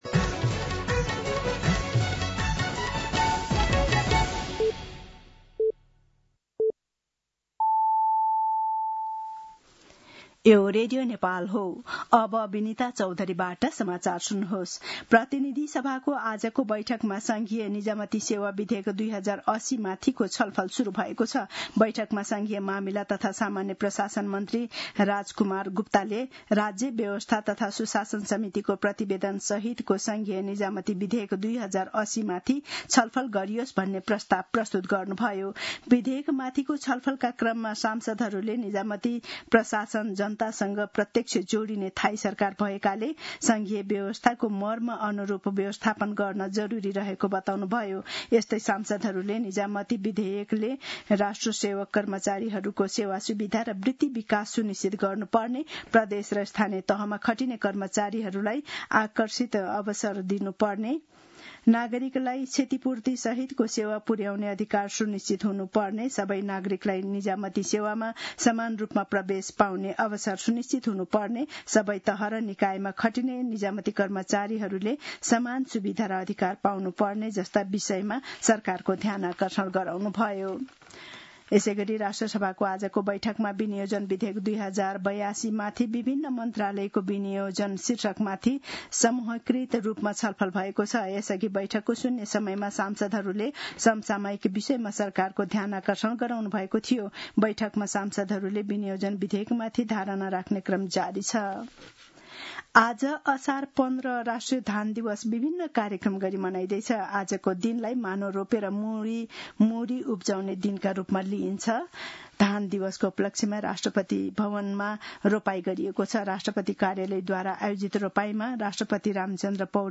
दिउँसो ४ बजेको नेपाली समाचार : १५ असार , २०८२
4-pm-News-3-15.mp3